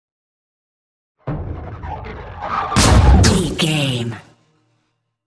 Double hit with whoosh shot explosion
Sound Effects
intense
tension
woosh to hit